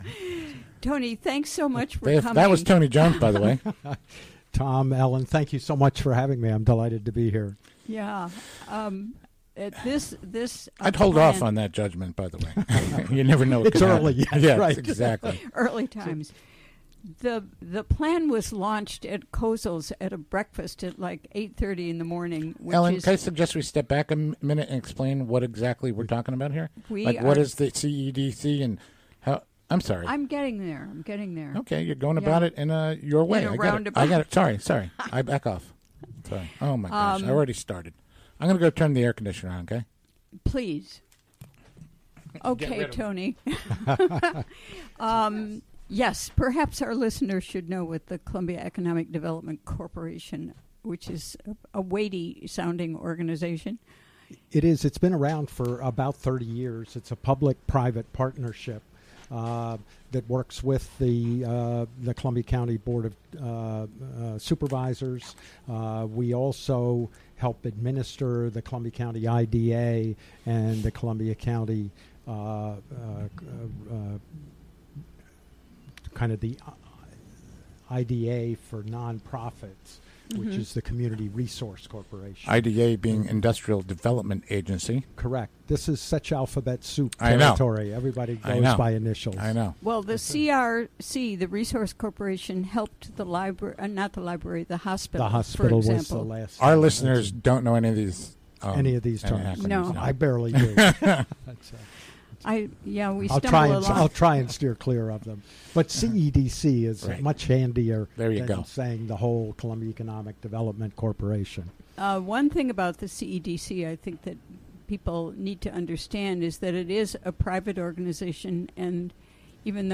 Interviewed
Recorded during the WGXC Afternoon Show.